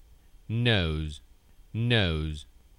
Haz clic para escuchar la pronunciación de las palabras: